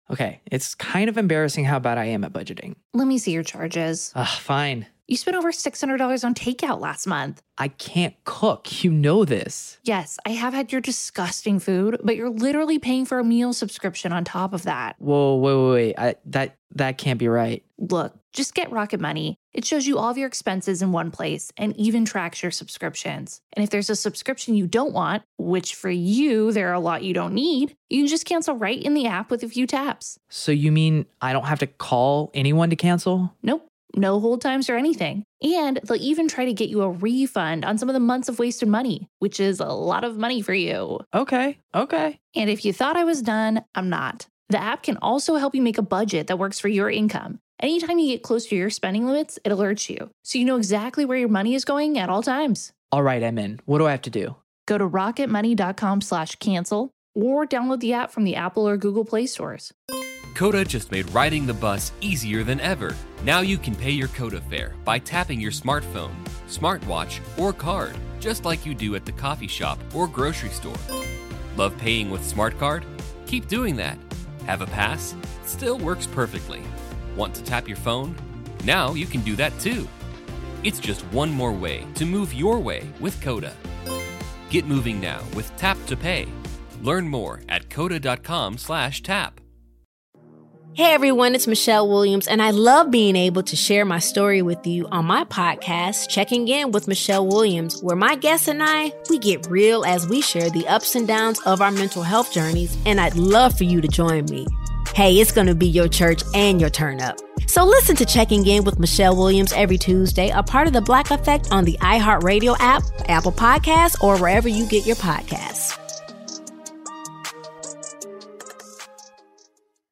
Listen to Part 1 of 2 as Scott Ferrall call an Atlanta Thrashers game against the Pittsburgh Penguins in March of 2000